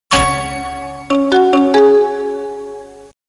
Рингтон Сигнал на SMS